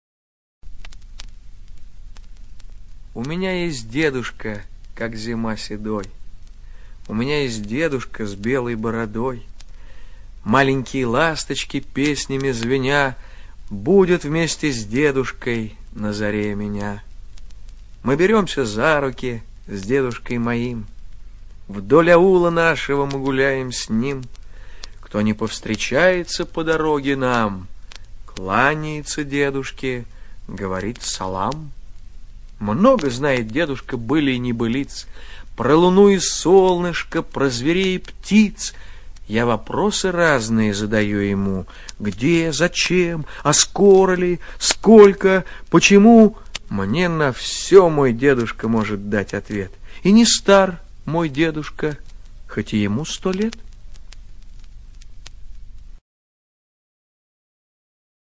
ЧитаетТабаков О.